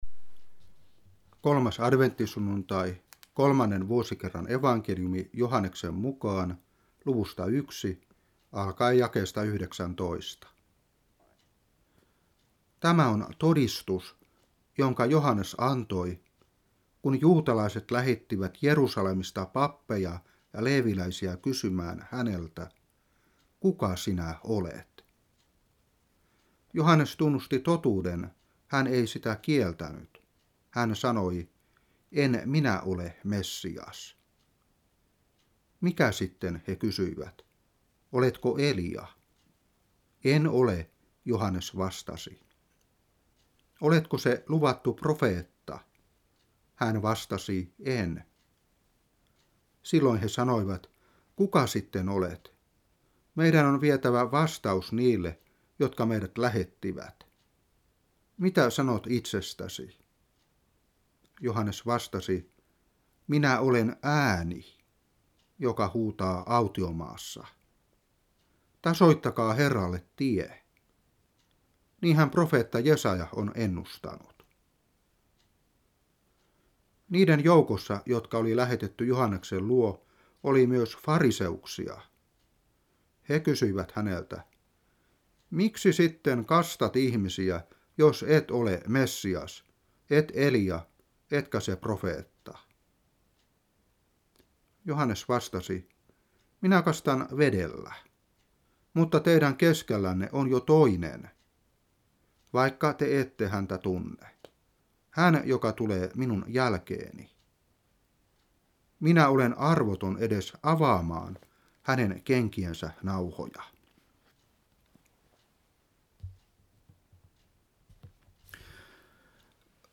Saarna 2020-12.